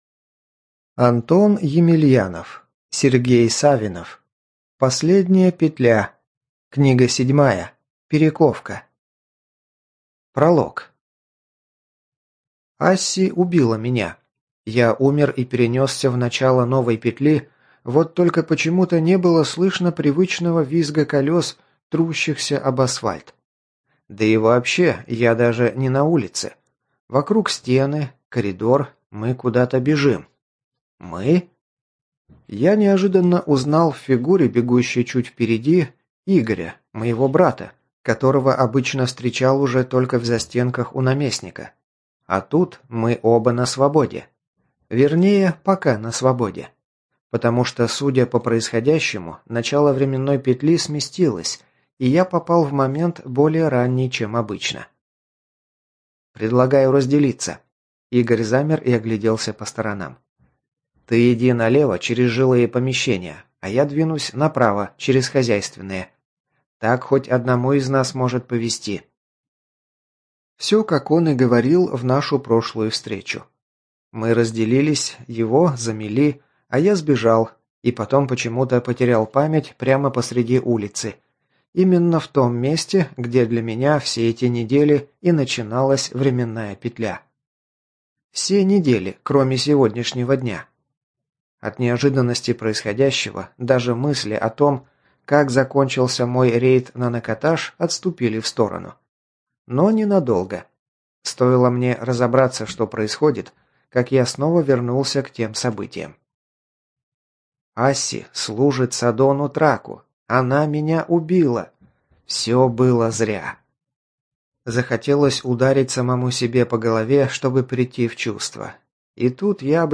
ЖанрФантастика, Фэнтези